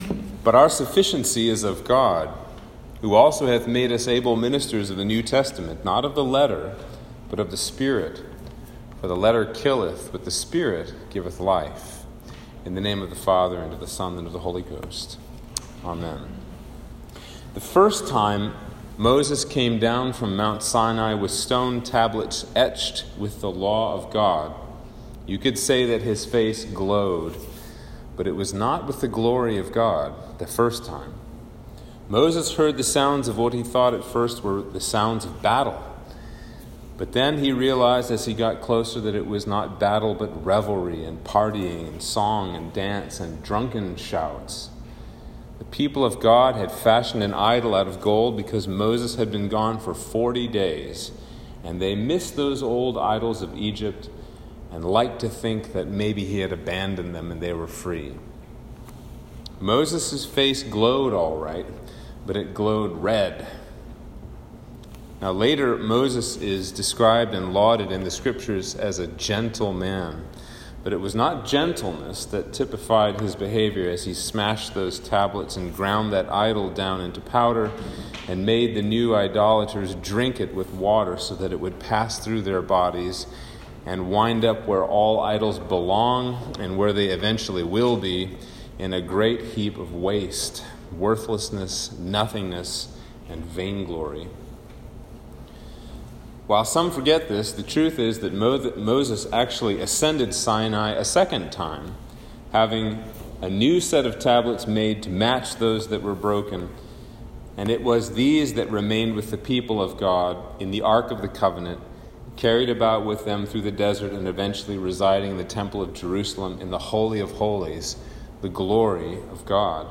Sermon for Trinity 12